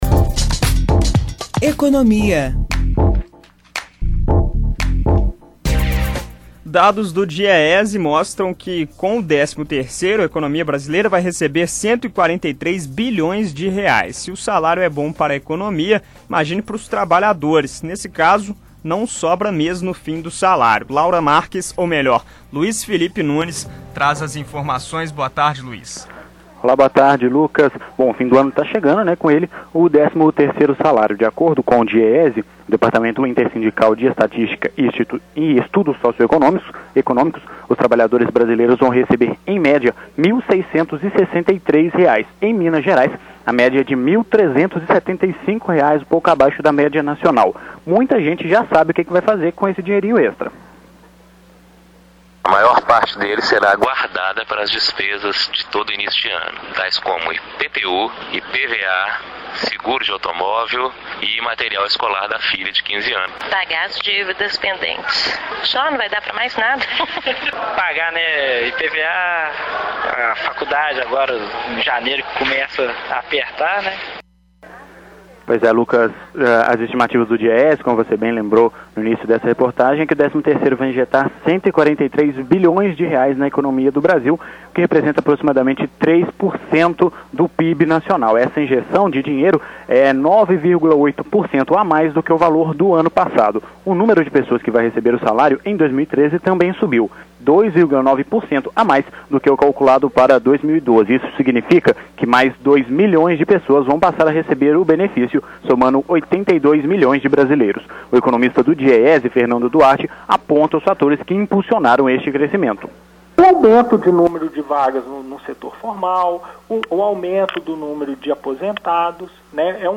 Economia A: Com a proximidade do Natal, o décimo terceiro promete esquentar a economia brasileira com a injeção de 143 bilhões de Reais no final do ano, sendo a maior parte em consumo.